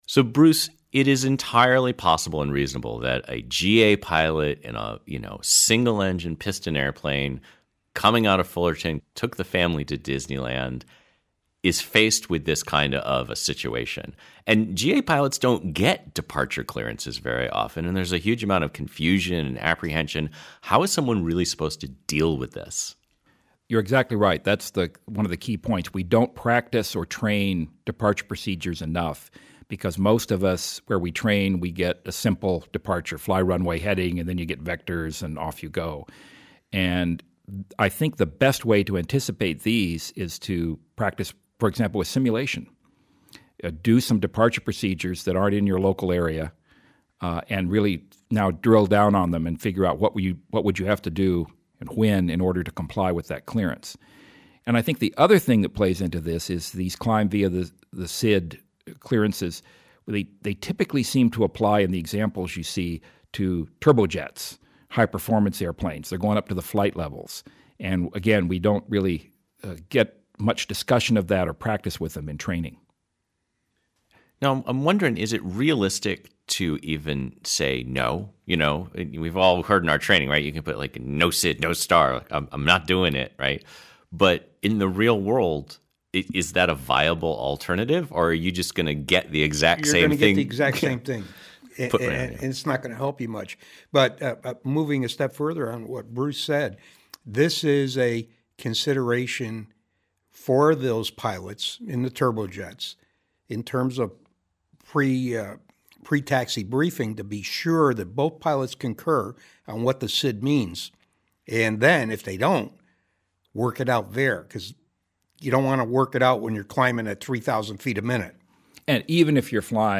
Exception_out_of_fullerton_roundtable.mp3